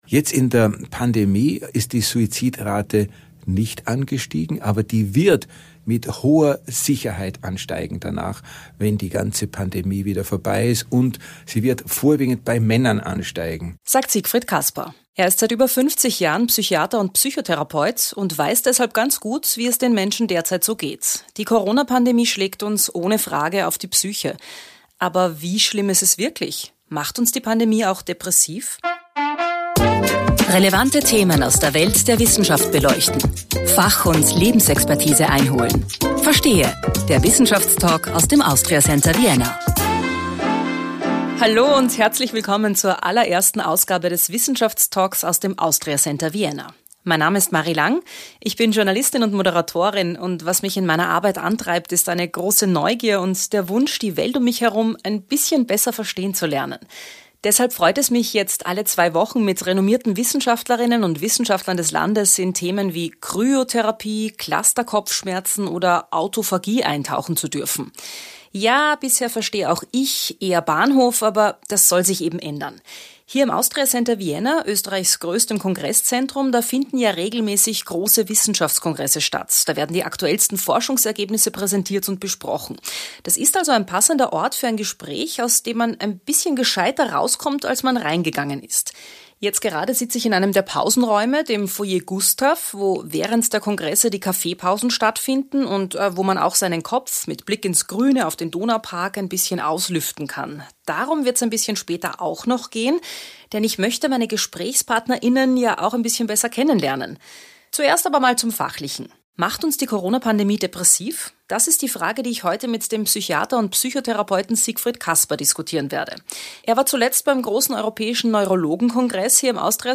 Der Wissenschaftstalk aus dem Austria Center Vienna Podcast
im Foyer G des Austria Center Vienna über depressive Verstimmung, Depression und Suizid.